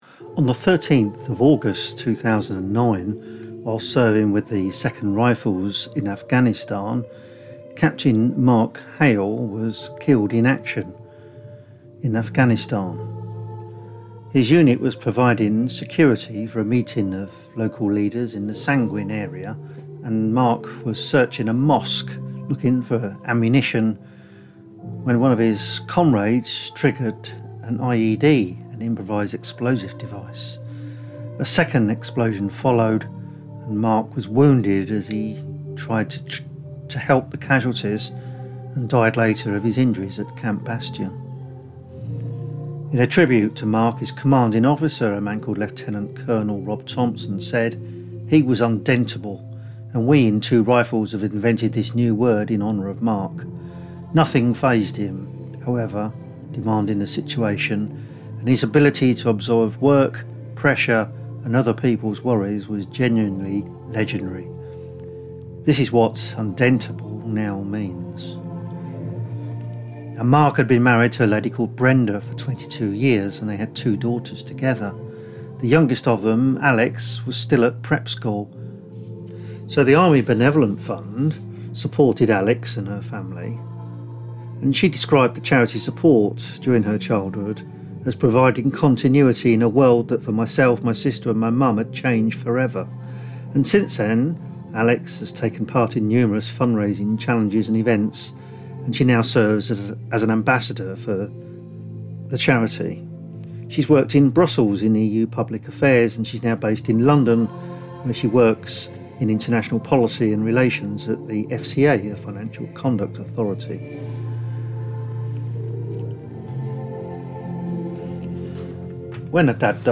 In this special episode we join The Army Benevolent Fund’s “Frontline Walk” from the Somme to Ypres, via Vimy Ridge.